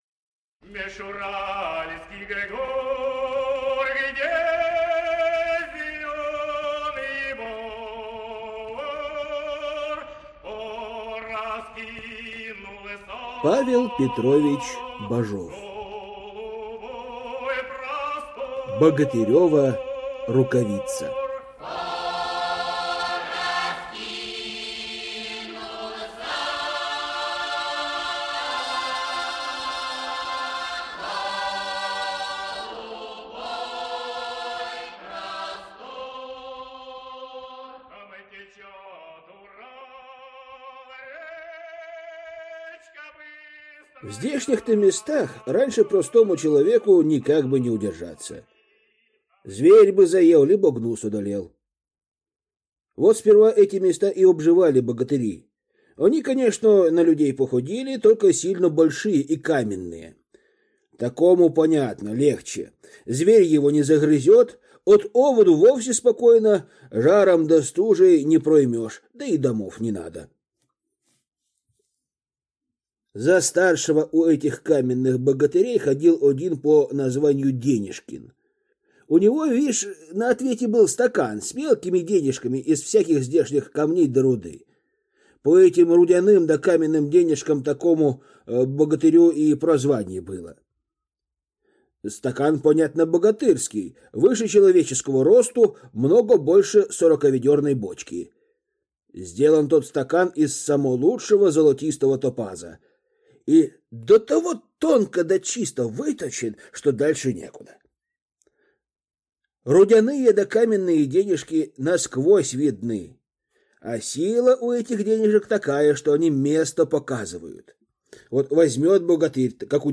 Аудиокнига Богатырева рукавица | Библиотека аудиокниг